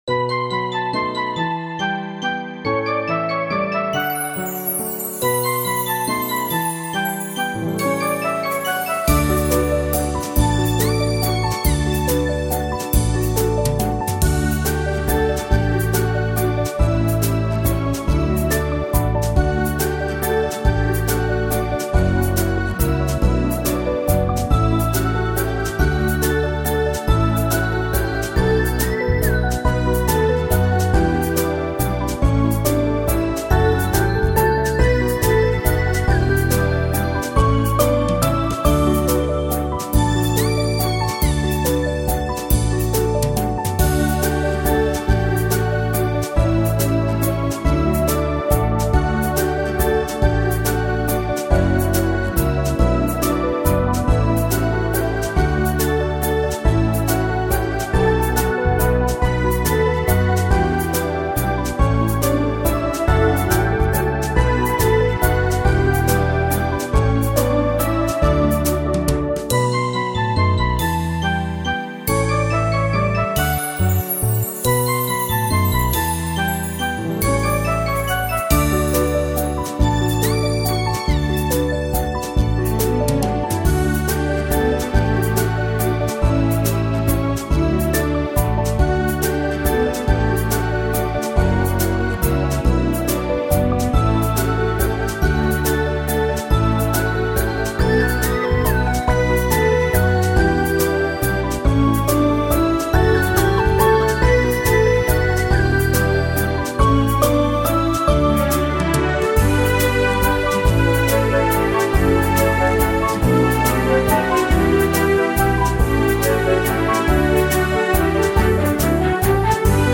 Фонограмма минус